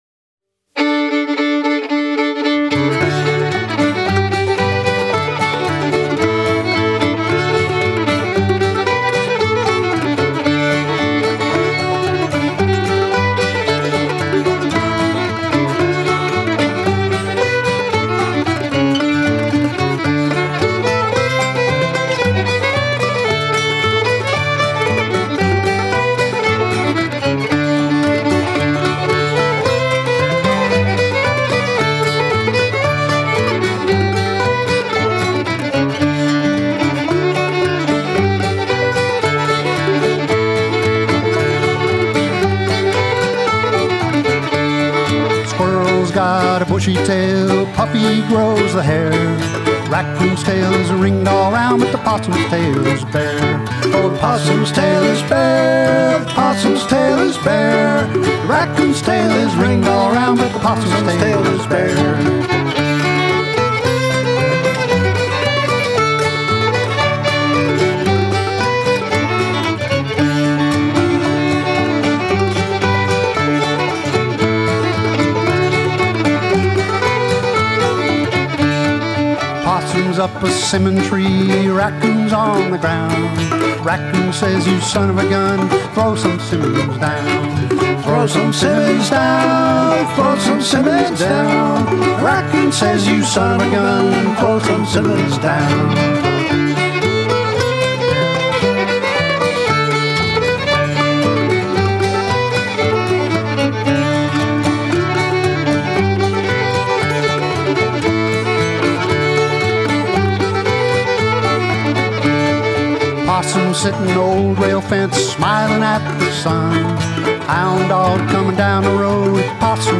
Old Time for sure but we like to think of ourselves as a transitional string band.
We recorded these with our guitar player